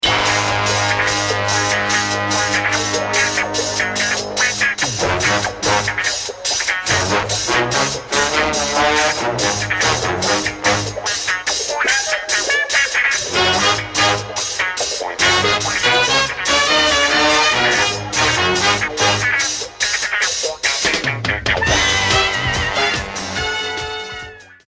avec les cuivres bien gras, la wha wha et les charleys